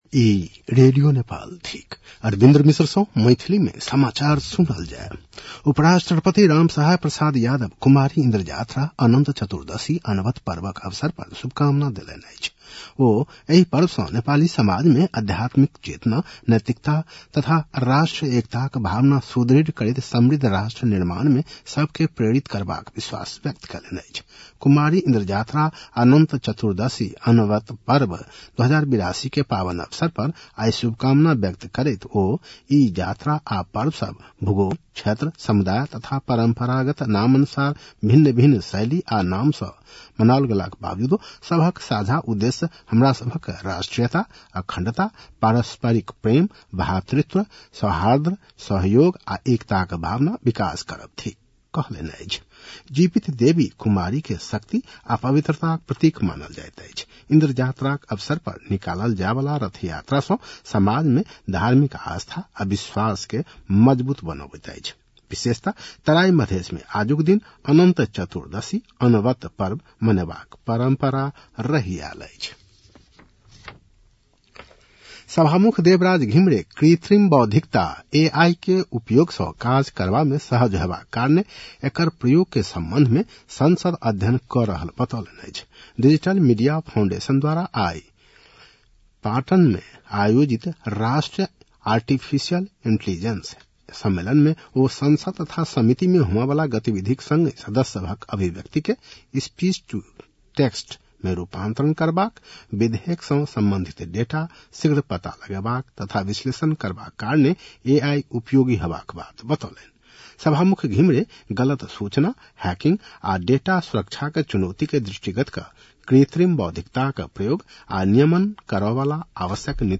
मैथिली भाषामा समाचार : २१ भदौ , २०८२